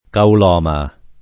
[ kaul 0 ma ]